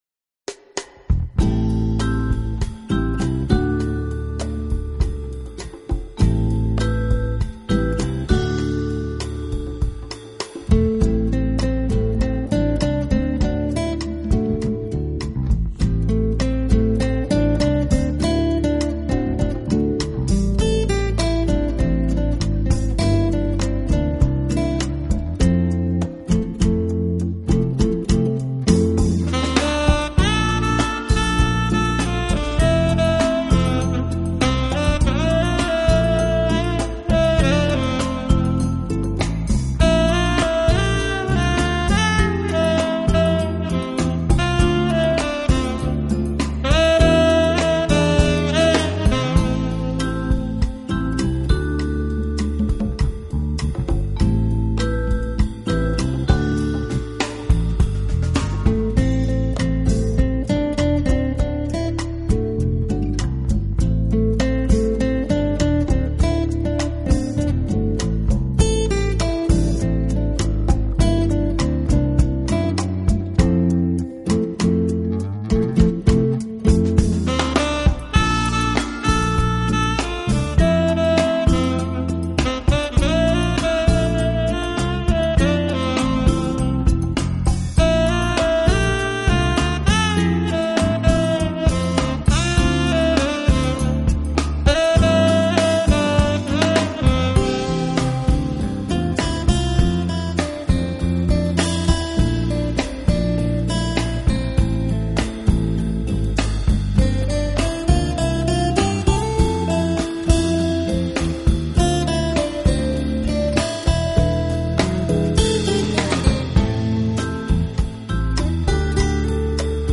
【爵士专辑】